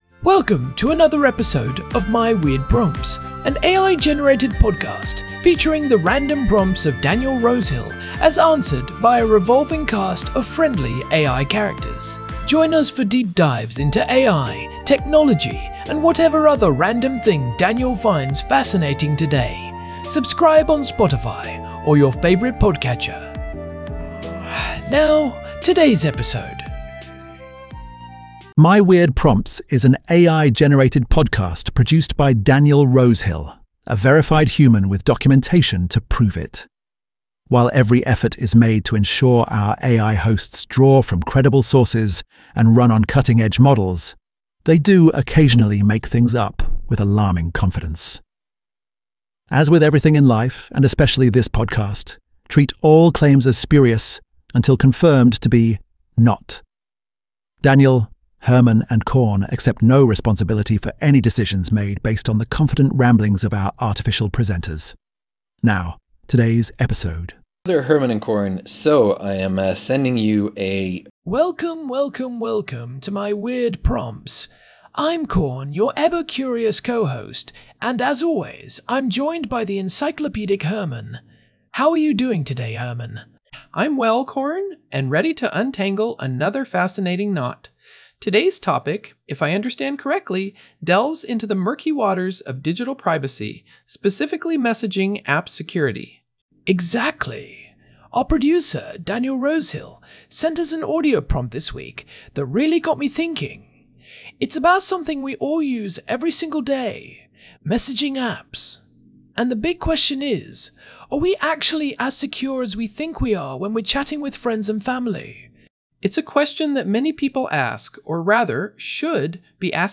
AI-Generated Content: This podcast is created using AI personas.
TTS Engine chatterbox-tts